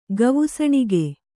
♪ gavusaṇige